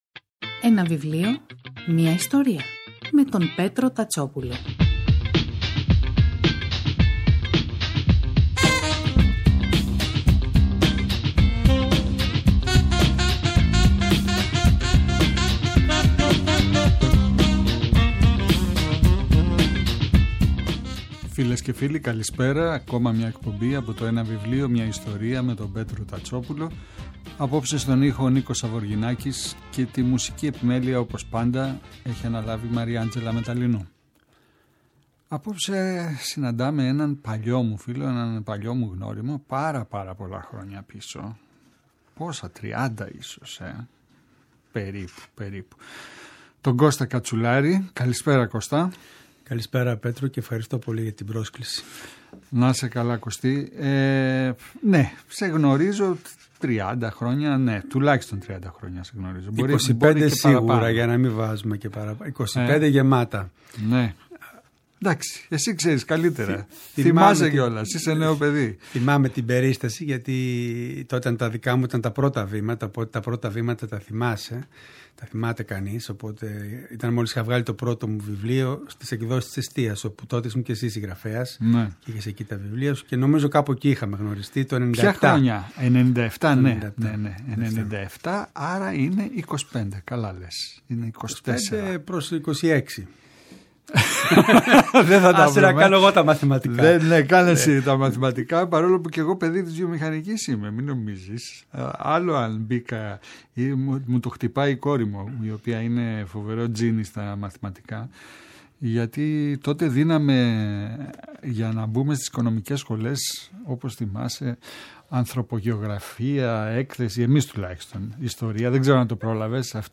Η εκπομπή “Ένα βιβλίο, μια ιστορία” του Πέτρου Τατσόπουλου, κάθε Σάββατο και Κυριακή, στις 5 το απόγευμα στο Πρώτο Πρόγραμμα της Ελληνικής Ραδιοφωνίας παρουσιάζει ένα συγγραφικό έργο, με έμφαση στην τρέχουσα εκδοτική παραγωγή, αλλά και παλαιότερες εκδόσεις.